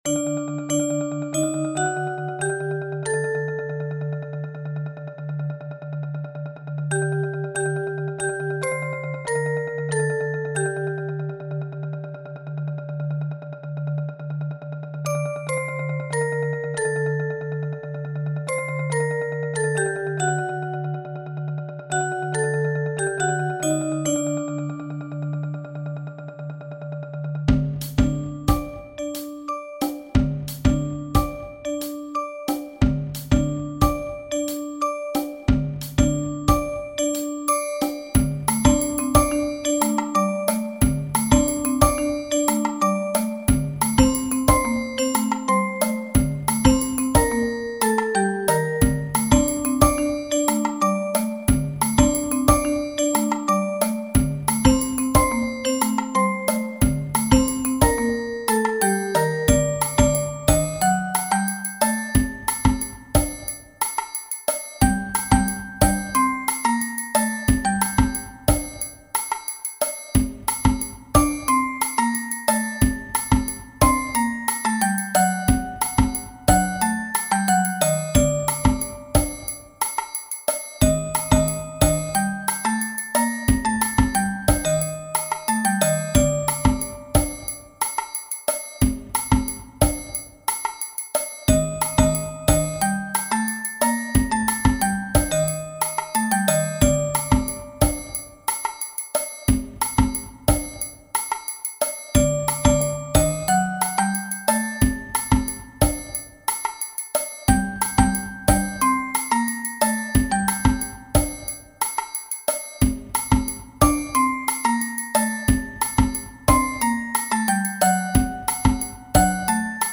HIJAZ – janvier 2026 – 90 bpm